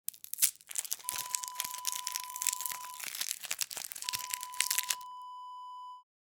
Candy Wrapper Crinkle Wav Sound Effect
Description: The sound of opening a candy wrapper
Properties: 48.000 kHz 24-bit Stereo
A beep sound is embedded in the audio preview file but it is not present in the high resolution downloadable wav file.
Keywords: candy, wrapper, plastic, crinkle, crinkling
candy-wrapper-crinkle-preview-1.mp3